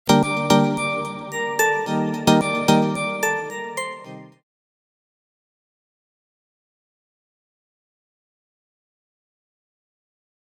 • Качество: 320, Stereo
без слов